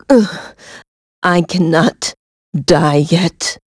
Ripine-Vox_Dead.wav